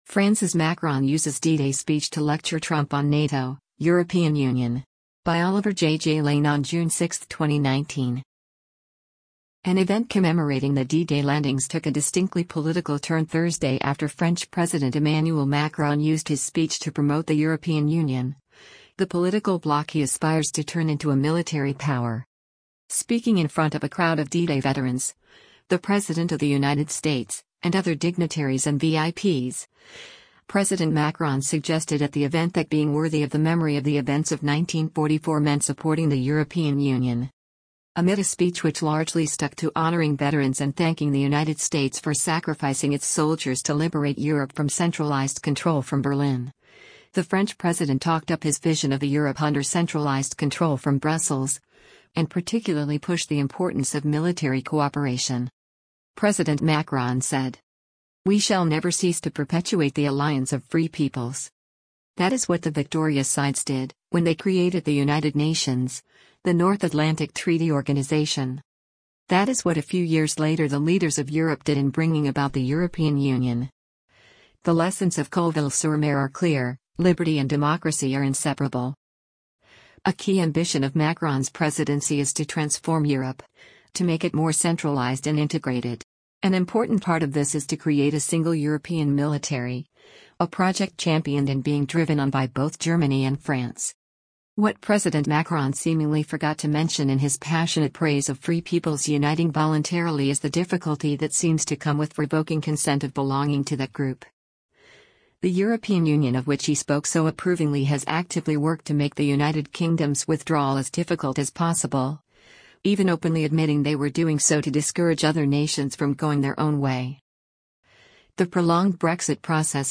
France’s Macron Uses D-Day Speech to Lecture Trump on NATO, European Union
Speaking in front of a crowd of D-Day veterans, the President of the United States, and other dignitaries and VIPs, President Macron suggested at the event that being “worthy” of the memory of the events of 1944 meant supporting the European Union.